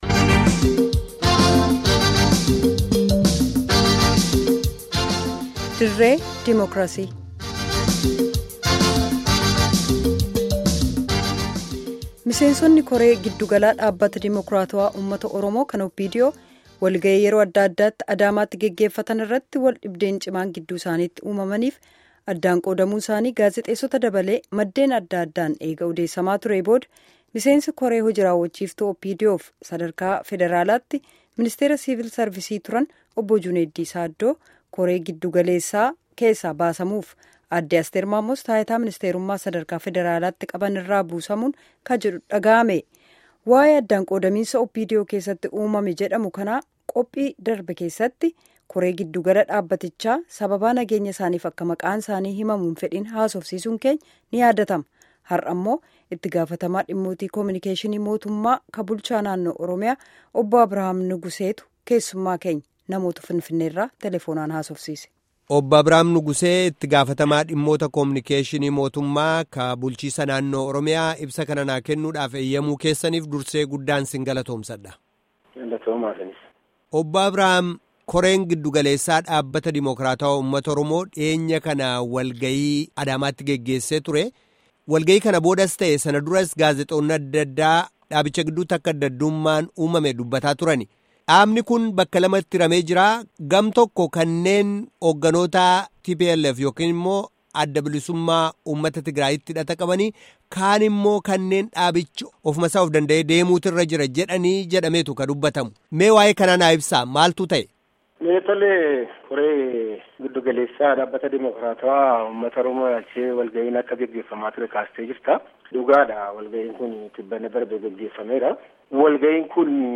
Gaaffii fi deebii kutaa 1ffaa